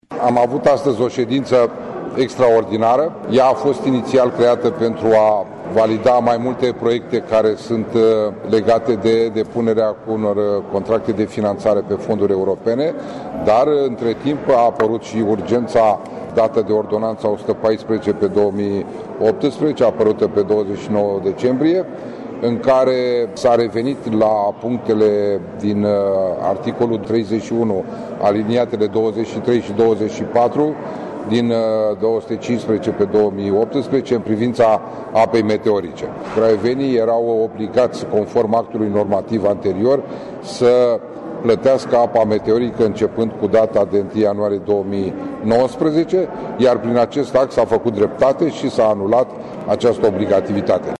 Mihail Genoiu, primarul municipiului Craiova: